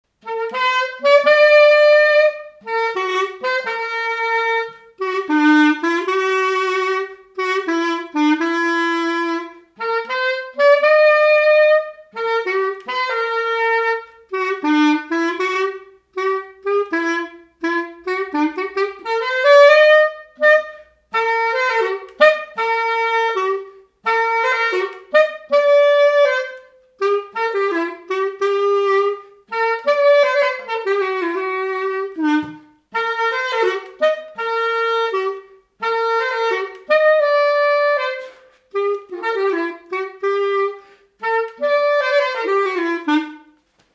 Résumé instrumental
Usage d'après l'informateur circonstance : fiançaille, noce
Catégorie Pièce musicale inédite